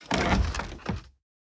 Chest Open 1.ogg